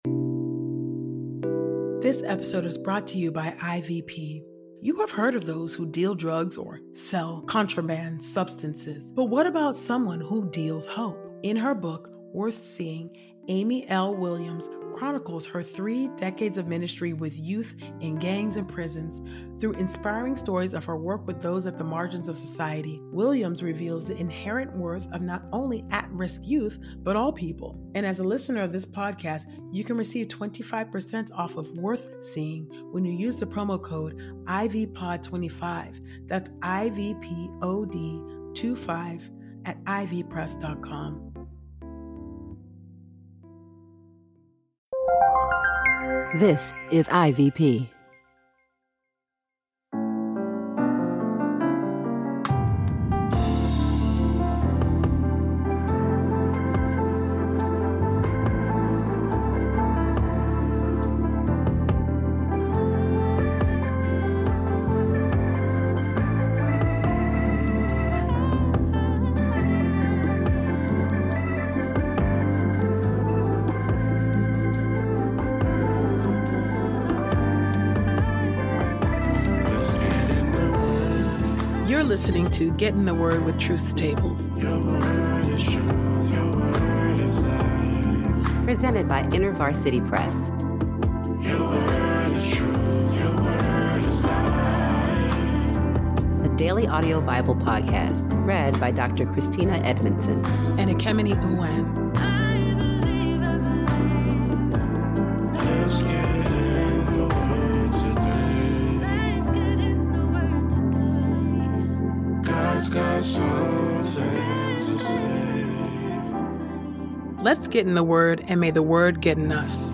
Sound engineering is from Podastery Studios